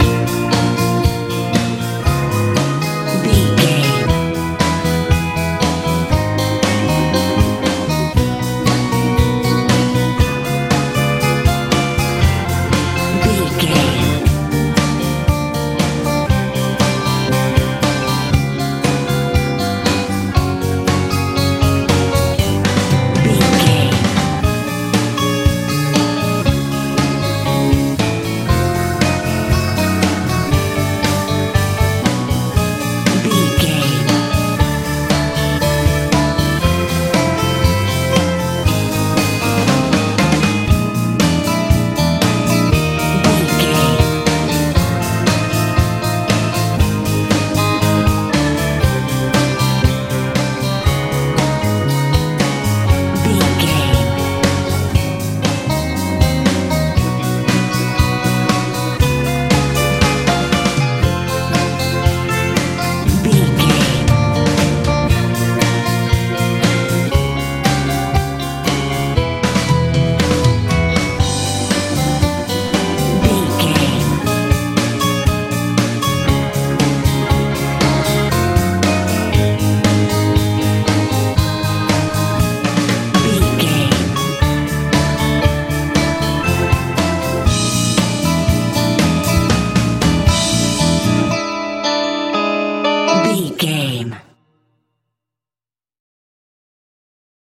lite pop feel
Ionian/Major
B♭
groovy
organ
electric guitar
bass guitar
drums
80s
90s